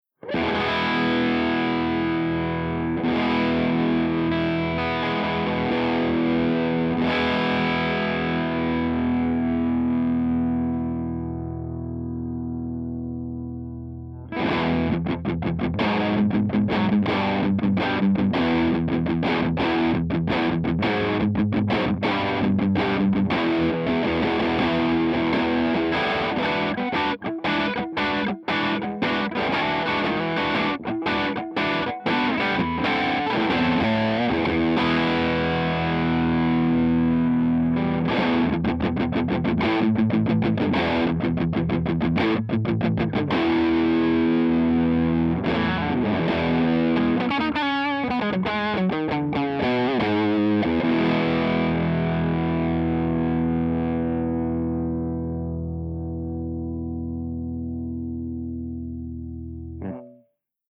084_MARSHALLJTM60_CH2DRIVE_HB